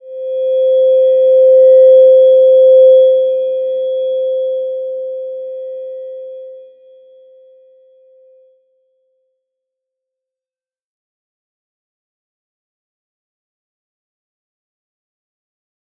Slow-Distant-Chime-C5-mf.wav